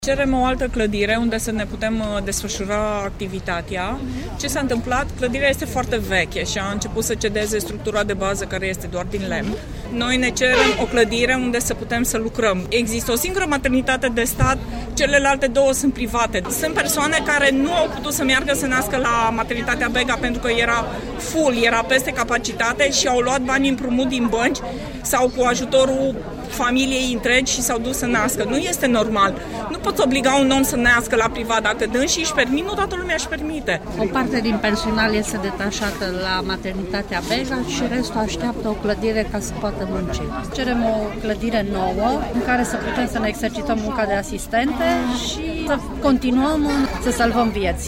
Aproximativ 200 de angajați de la Maternitatea Odobescu, din Timișoara, au ieșit la o acțiune de protest pentru a solicita o clădire în care să își desfășoare activitatea.
voxuri-Odobescu.mp3